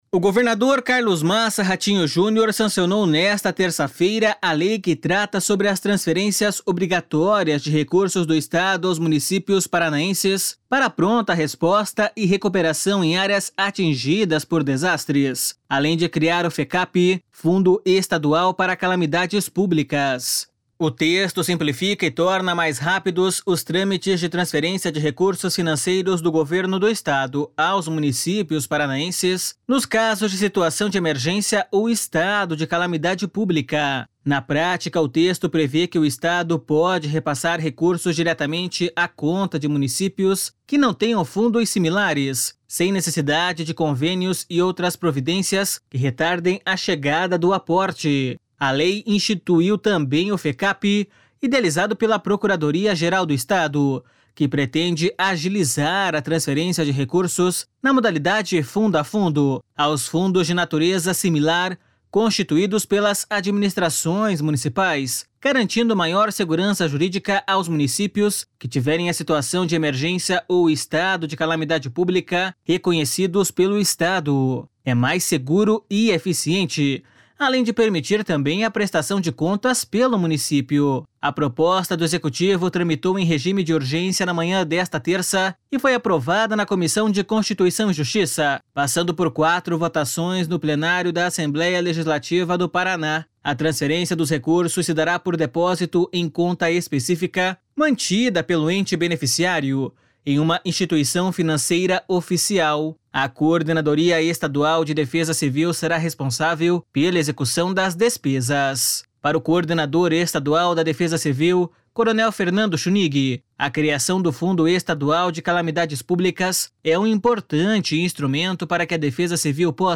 Para o coordenador estadual da Defesa Civil, coronel Fernando Schunig, a criação do Fundo Estadual de Calamidades Públicas é um importante instrumento para que a Defesa Civil possa, em momentos de desastres, agilizar a resposta por parte do Governo do Estado para aqueles municípios prejudicados.// SONORA FERNANDO SCHUNIG.//